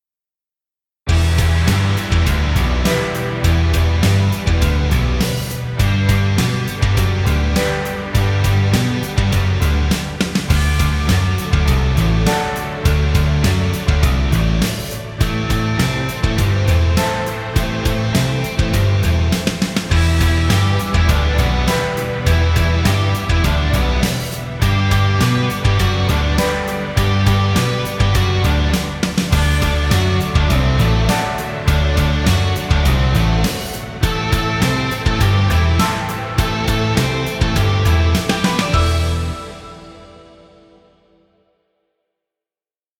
energetic inspirational track. Rock music for rock band.
Powerful rock music for video.